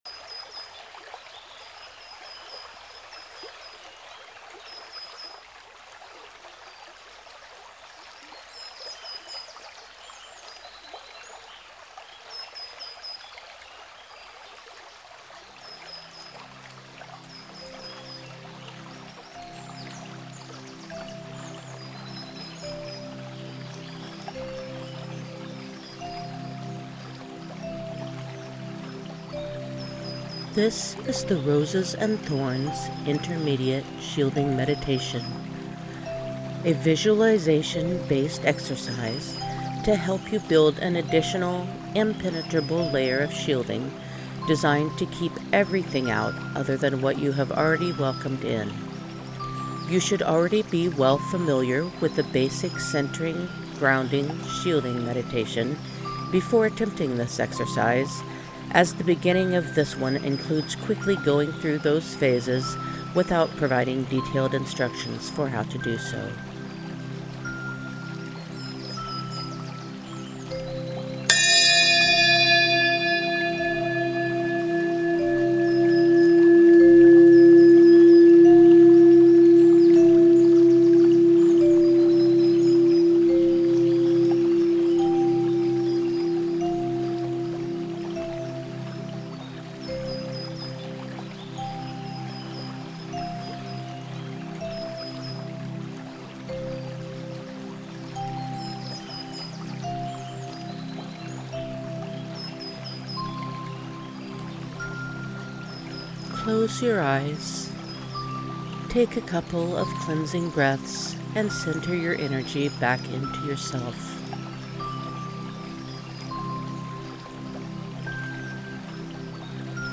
As usual, the guided meditation track uses a chime to begin this meditation • Close your eyes, take a couple of cleansing breaths, and center your energy back into yourself.
GuidedMeditation-rosethorn.mp3